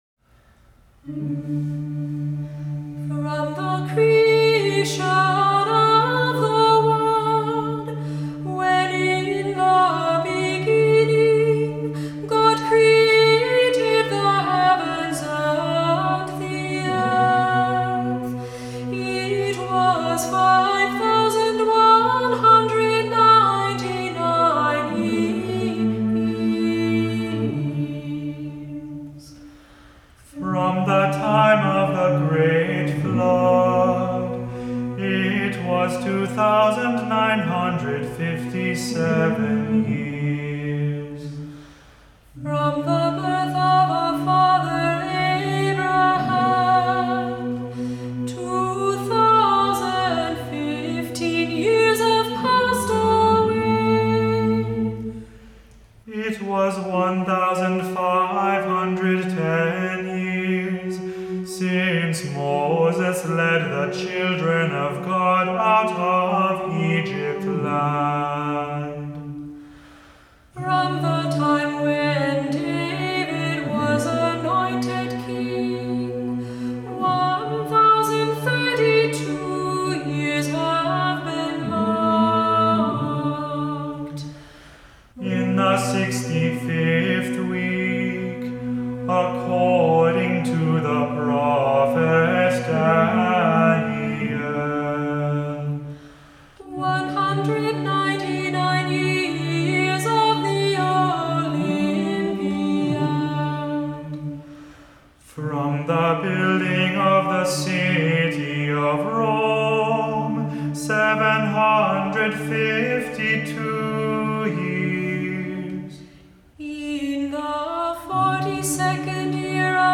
Voicing: Cantor